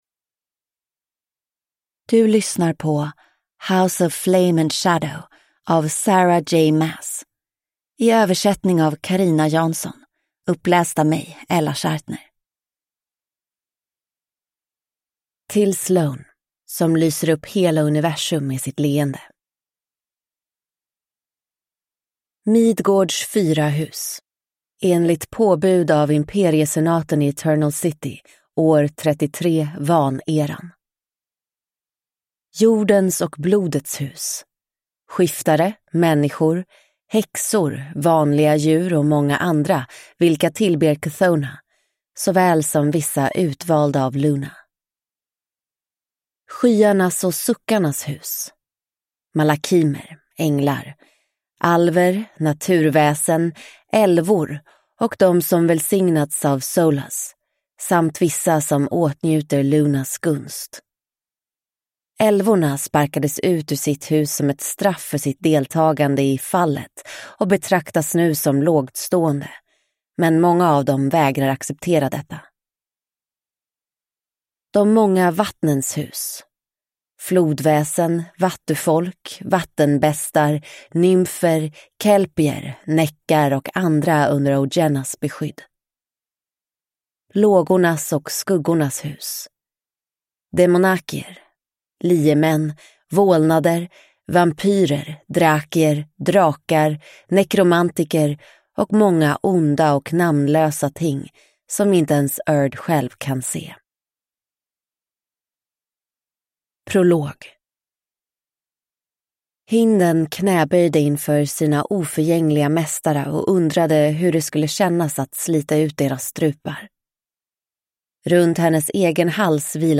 House of Flame and Shadow (Svensk utgåva) – Ljudbok